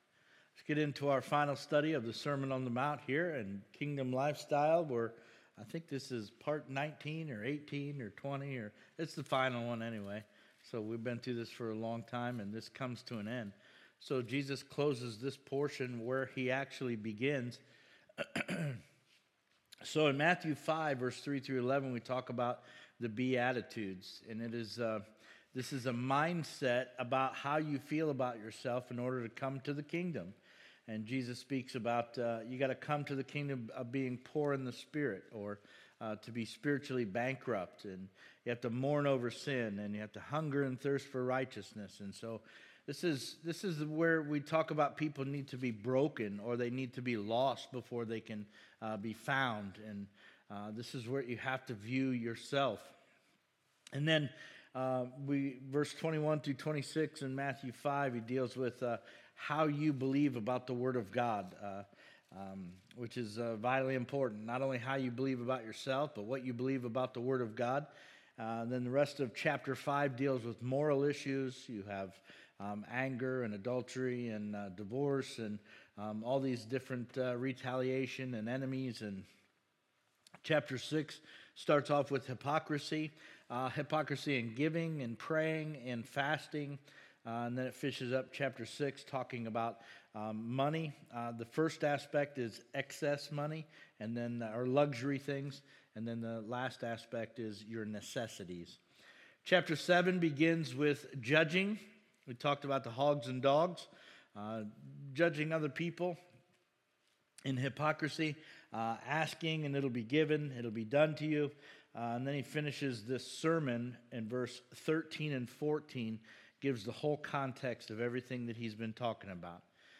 SERMONS -